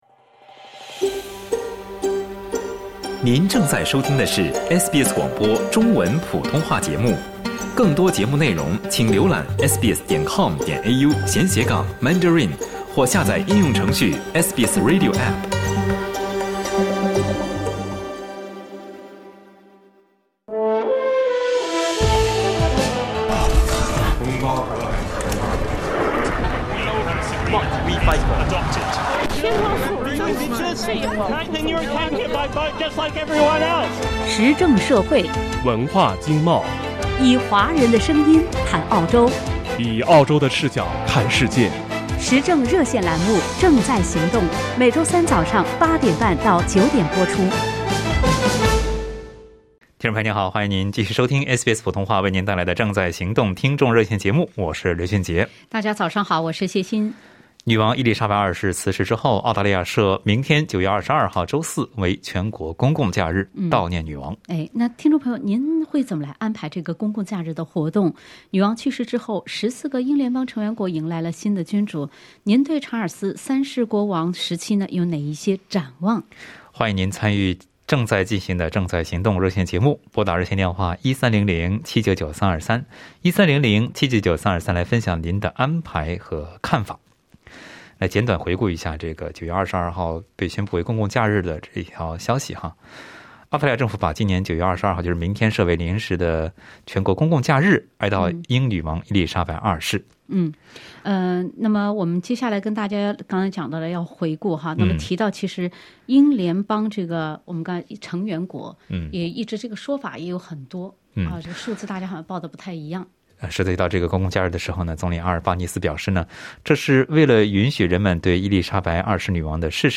在节目中，听友们就英国及王室对澳洲的贡献、殖民历史、君主制、推动共和制等表达了看法。